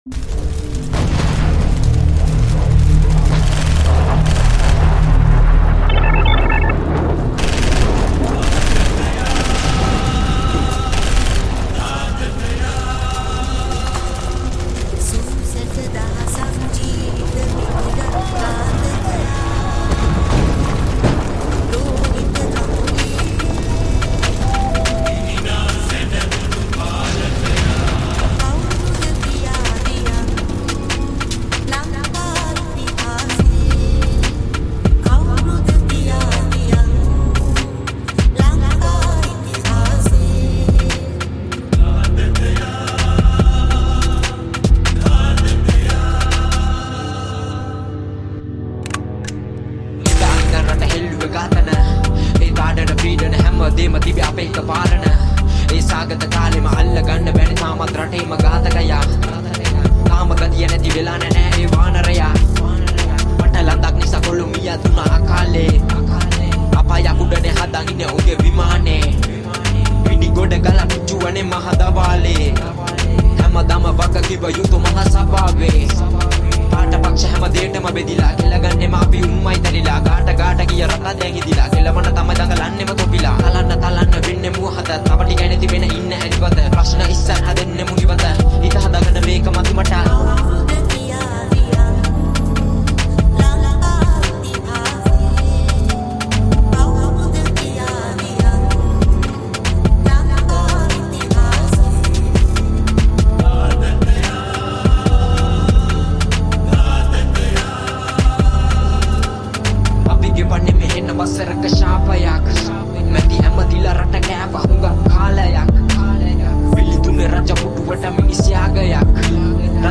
High quality Sri Lankan remix MP3 (4.1).
high quality remix